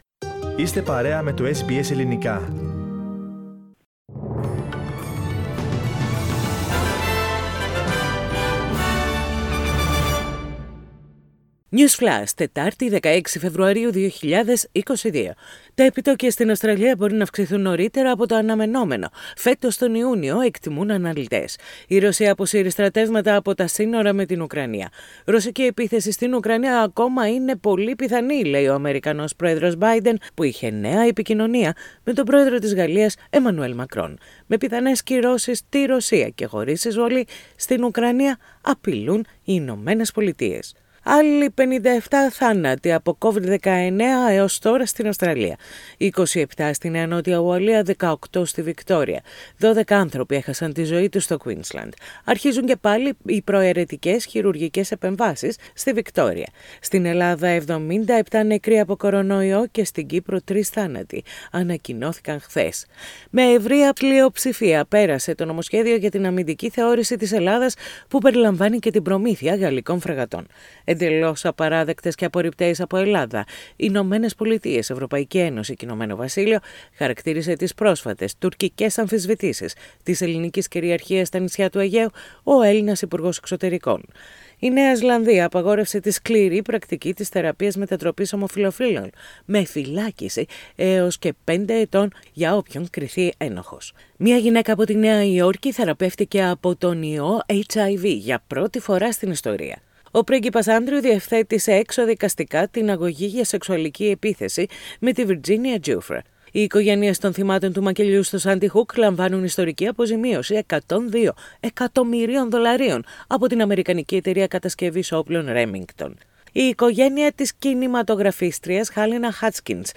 Greek News Flash - Wednesday 16.2.22
News in Greek.